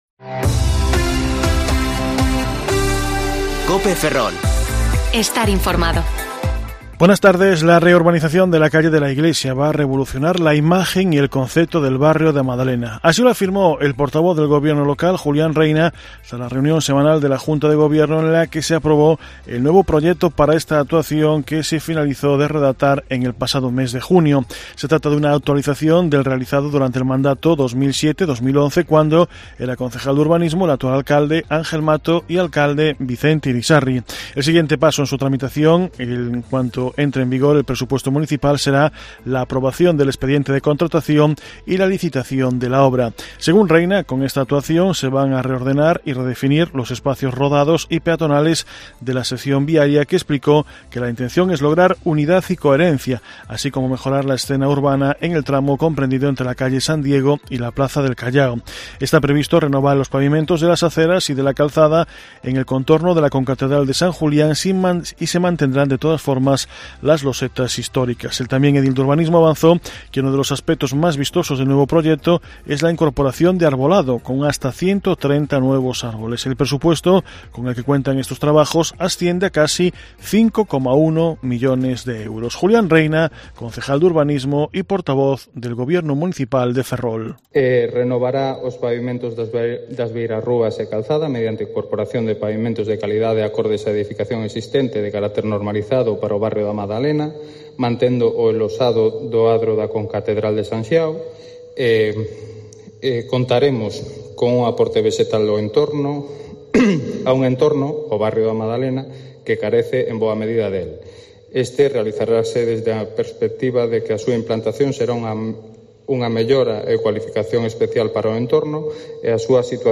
Informativo Mediodía COPE Ferrol 16/8/2021 (De 14,20 a 14,30 horas)